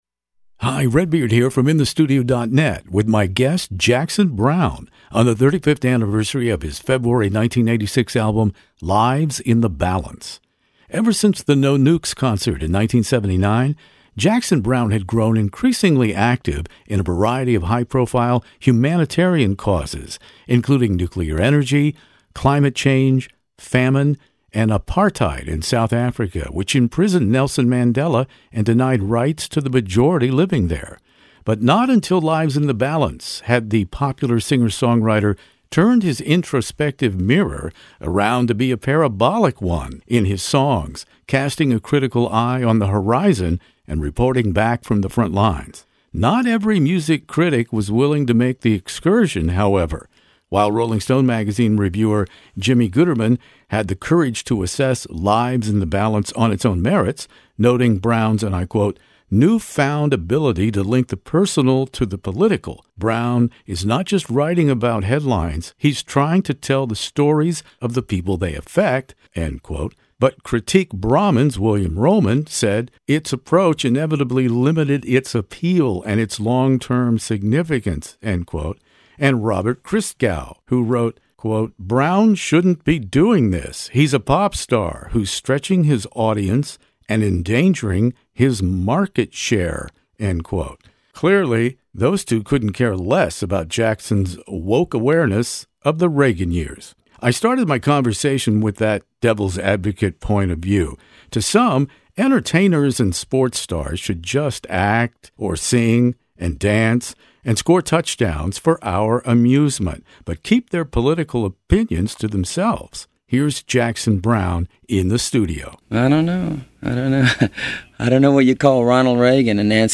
My guest In the Studio Jackson Browne does not provide one-ply fluff in this classic rock interview.